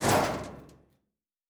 Metal Foley 5.wav